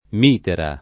mitera [ m & tera ]